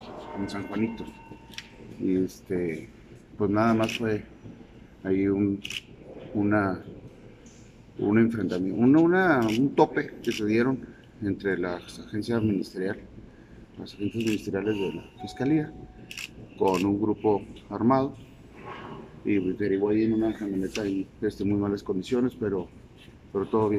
Audio. Fiscal general Roberto Fierro Duarte.
Fiscal-sobre-enfrentamiento-en-San-Juanito.mp3